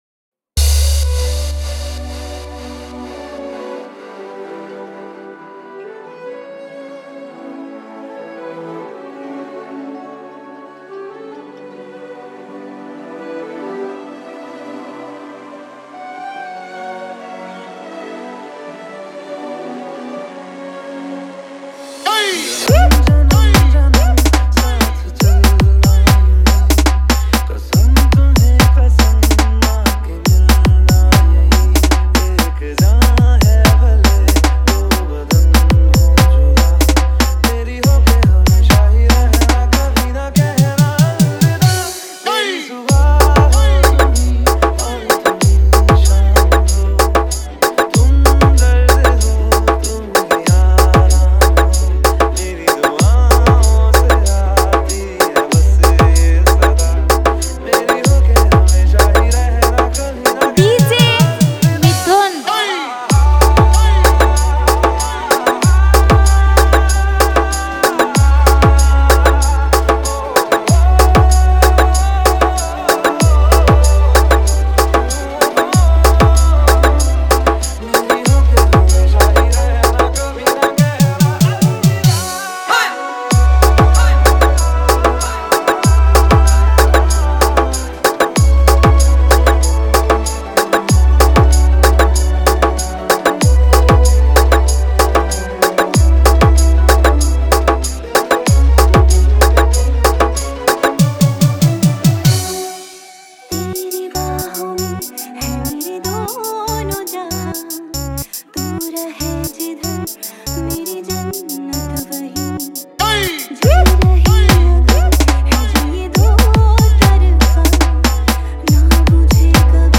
Heart Touching Love Story Mix 2021
Remix